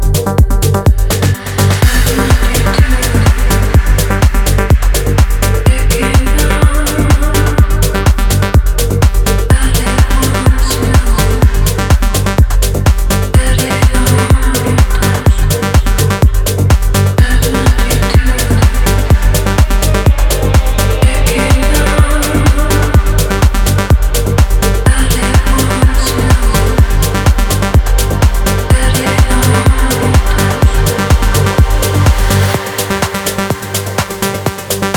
Жанр: Техно